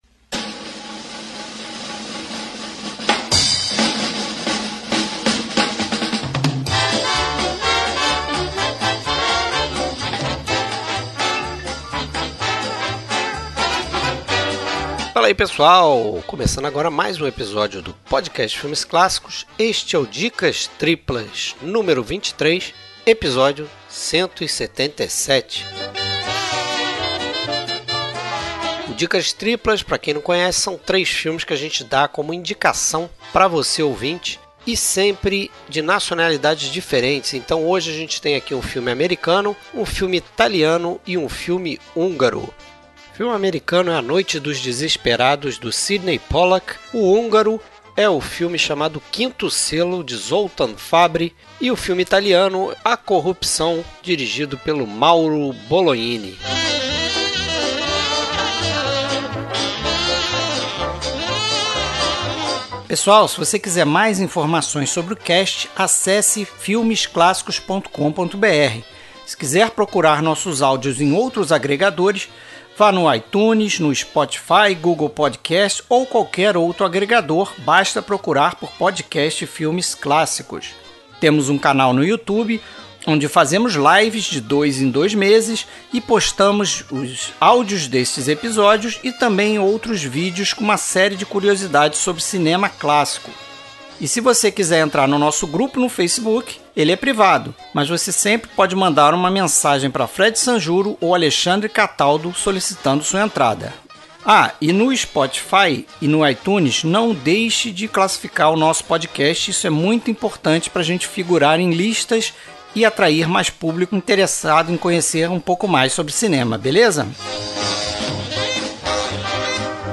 Trilha Sonora: Trilhas sonoras dos filmes comentados neste episódio.